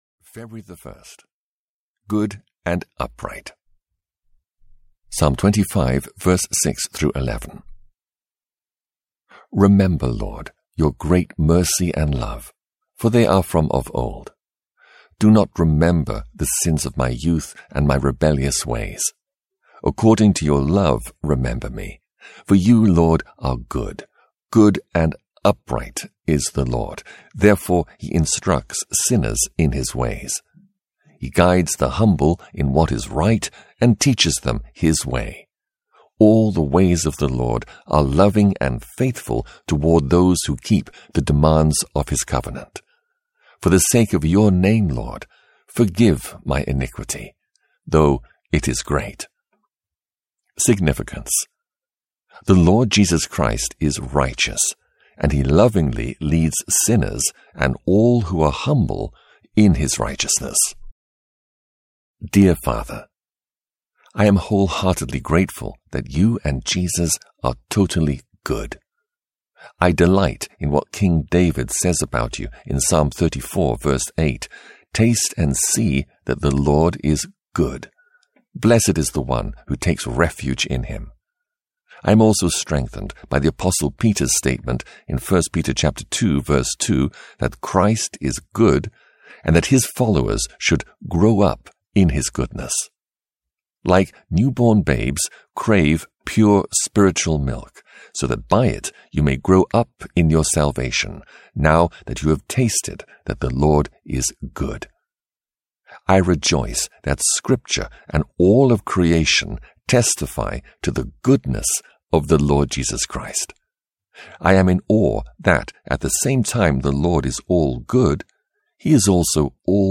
I Want to Know More of Christ Audiobook
17 Hrs – Unabridged